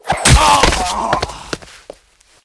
glove.ogg